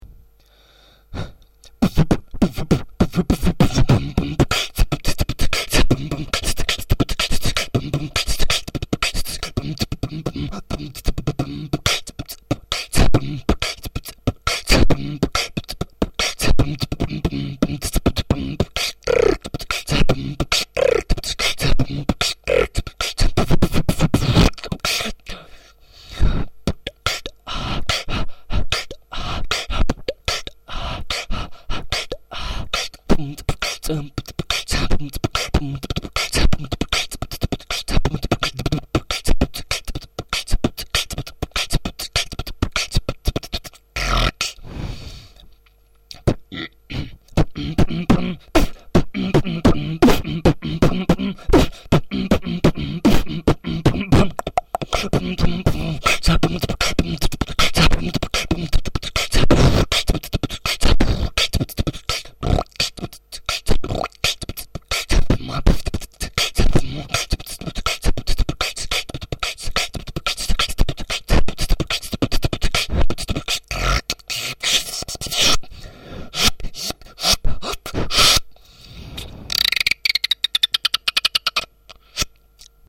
Форум российского битбокс портала » Реорганизация форума - РЕСТАВРАЦИЯ » Выкладываем видео / аудио с битбоксом » мой 1 бит) (зацените)))
1.Сбиваешься
2. Насала и робата почти не слышно
микрофон плохой), а голос робота я плохо делаю)